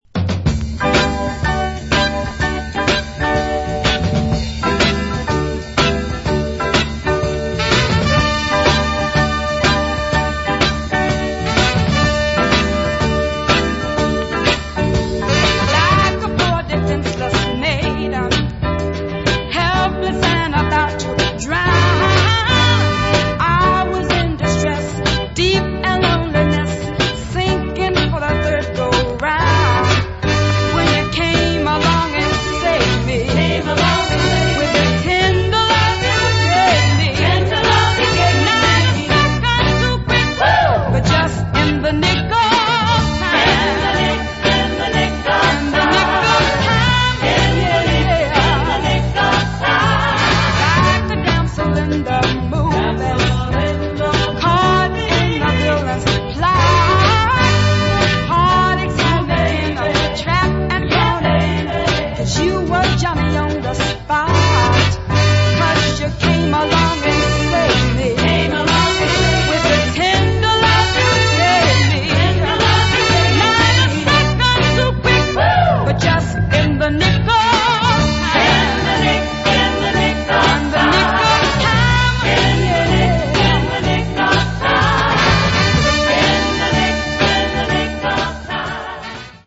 Recommended finger snapping late 60's Northern Soul dancer.